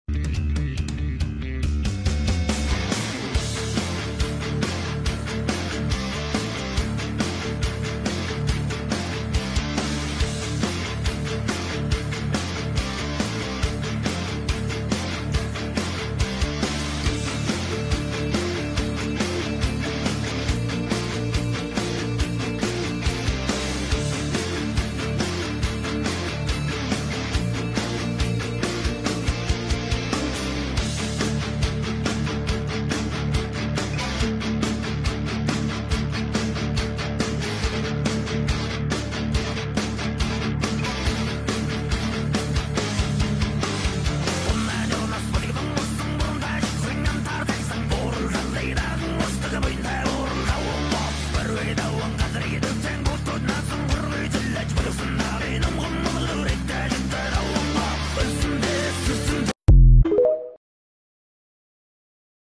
Before the main airshow last Saturday at Mallipo California Beach 2025, the Black Eagles took to the sky for their pre-training session.